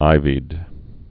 (īvēd)